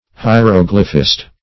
Search Result for " hieroglyphist" : The Collaborative International Dictionary of English v.0.48: Hieroglyphist \Hi`er*og"ly*phist\ (?; 277), n. One versed in hieroglyphics.